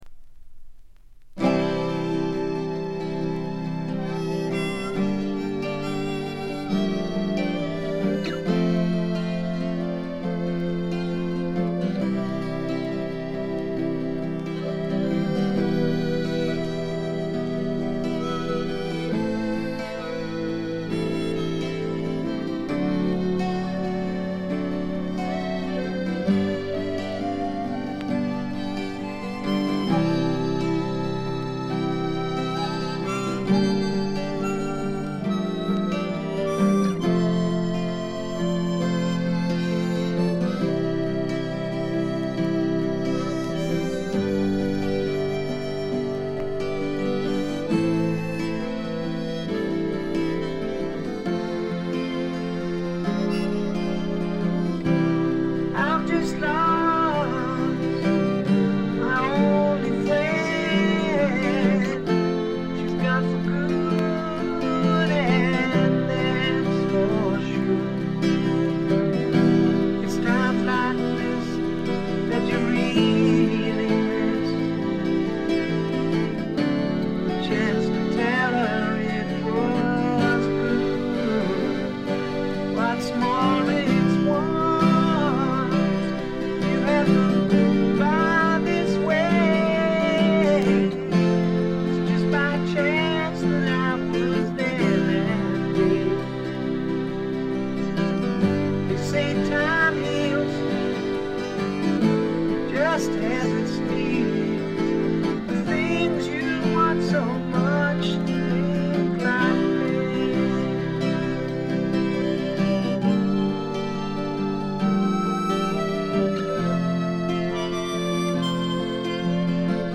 部分試聴ですが、軽微なチリプチ、バックグラウンドノイズ程度。
初期のマッギネス・フリントのようなスワンプ路線もありますが、それに加えて激渋ポップ感覚の漂うフォークロック作品です。
それにしてもこの人の引きずるように伸びのあるヴォーカルは素晴らしいでね。
試聴曲は現品からの取り込み音源です。